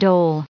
added pronounciation and merriam webster audio
869_dole.ogg